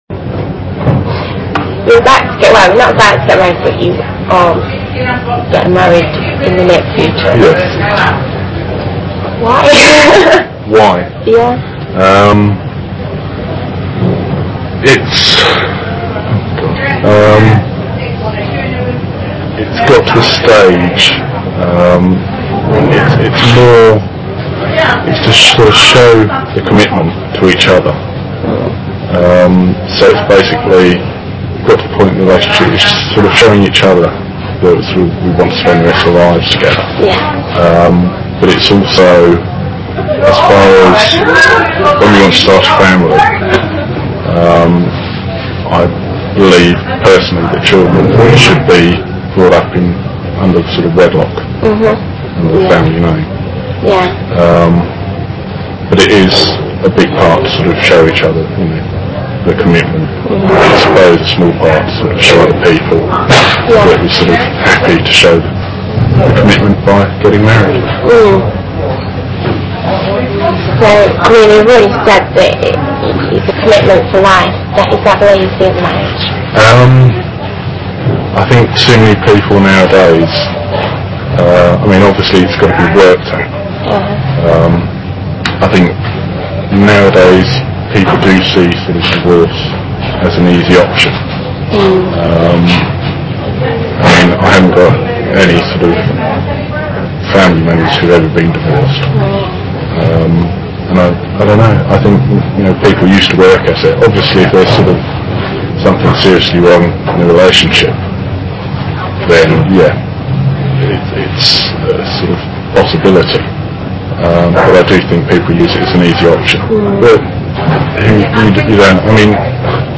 Una entrevista, una consulta médica o un talk show en la televisión pueden trascribirse, incluso con el uso de anotaciones completas, para indicar el ascenso o descenso en la entonación, las pausas o las dudas en la expresión, tal como se ha presentado en el extracto anterior, tomado de una entrevista a un joven.